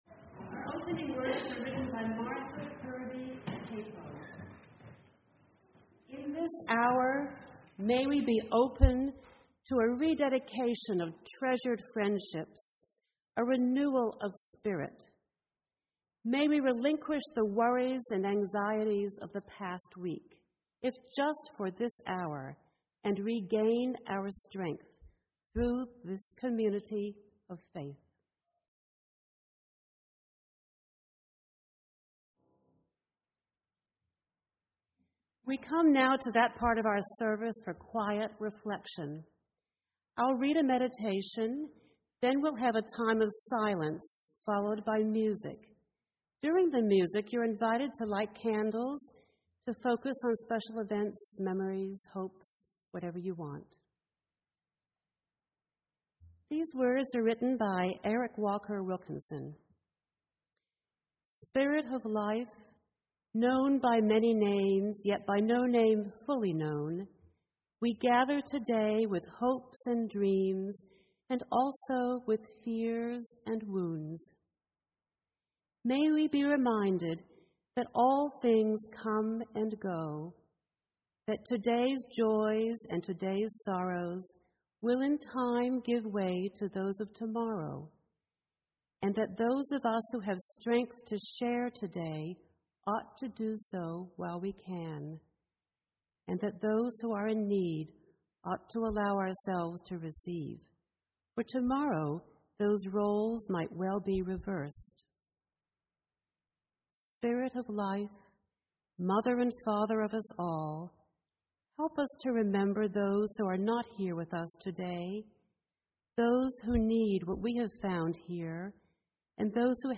Sermon: Keep your eyes upon Jesus